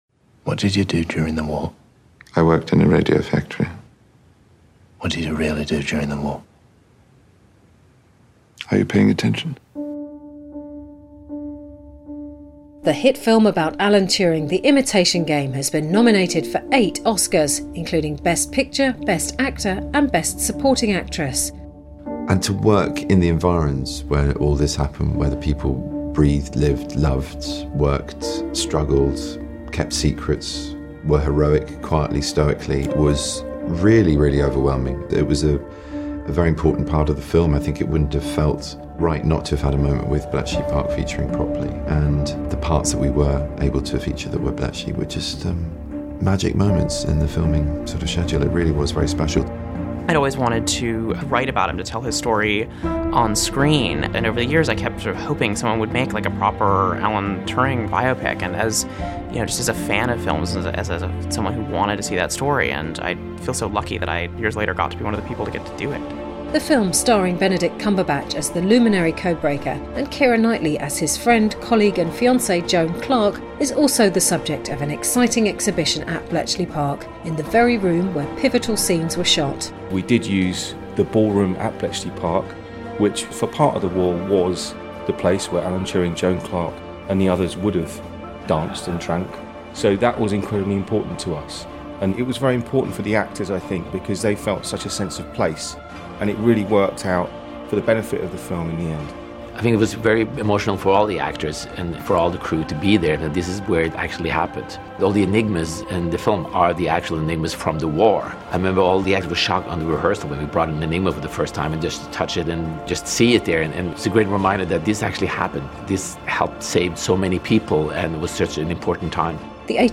Trailer, Music & Picture: © Black Bear Pictures/Studio Canal